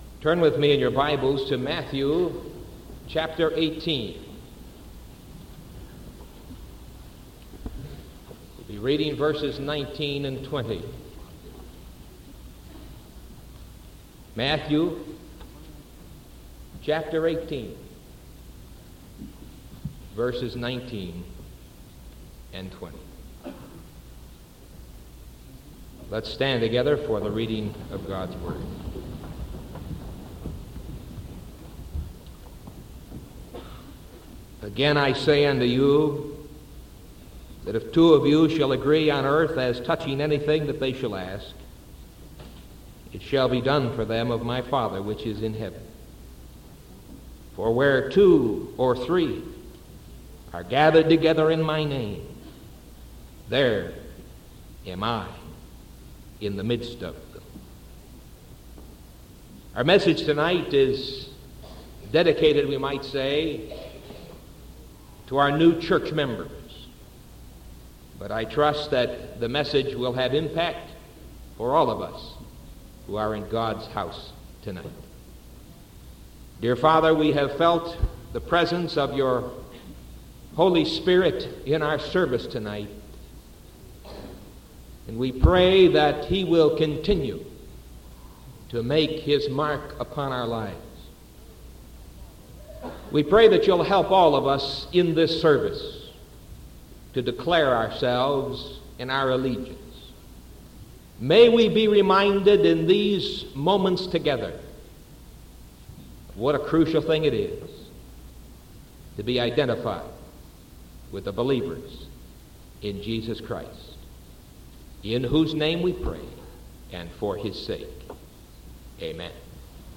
Sermon October 13th 1974 PM